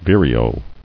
[vir·e·o]